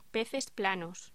Locución: Peces planos